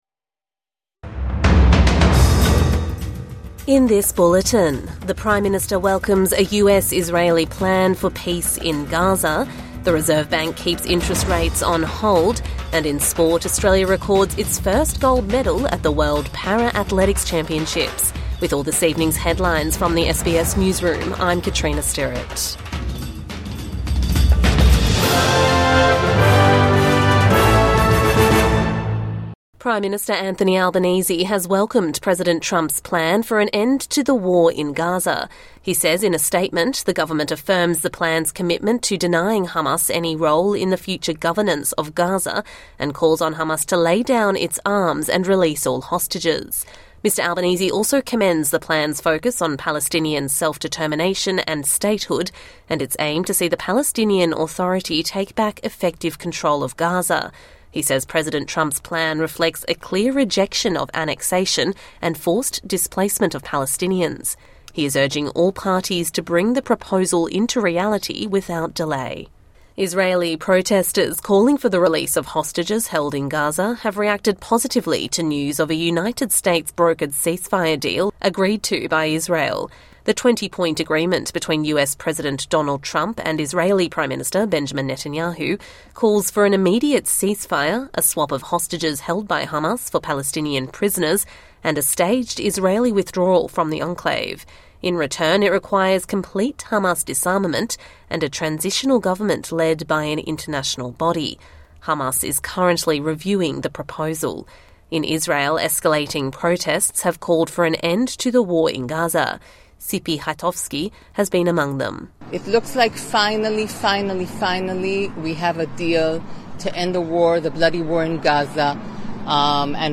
The Reserve Bank keeps interest rates on hold | Evening News Bulletin 30 September 2025